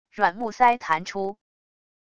软木塞弹出wav音频